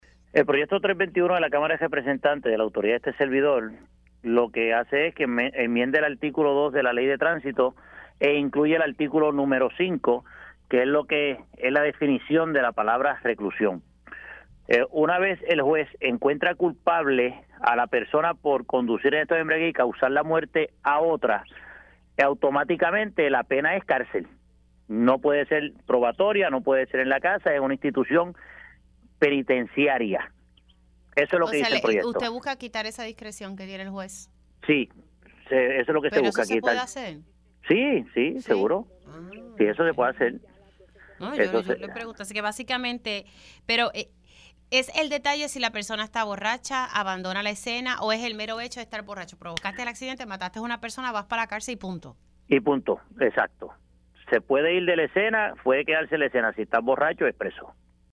505-FERNANDO-SANABRIA-REP-PNP-AUTOR-DE-MEDIDA-QUE-IMPONE-CARCEL-A-CONDUCTORES-BORRACHOS-QUE-CAUSEN-MUERTE-A-OTRO.mp3